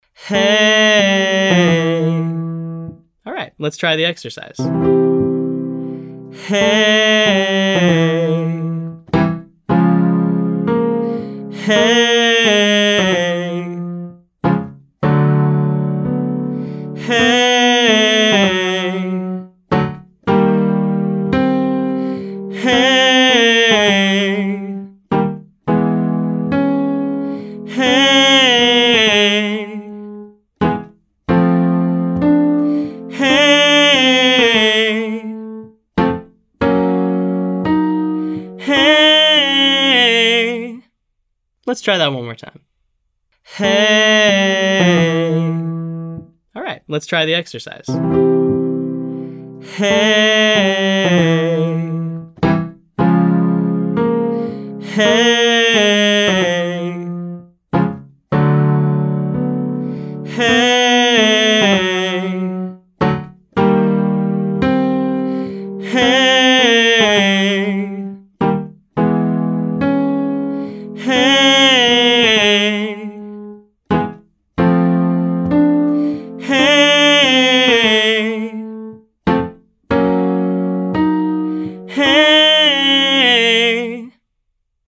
Our final exercise combines a fall with a short riff pattern.
Exercise: Hey (called), hey 6-543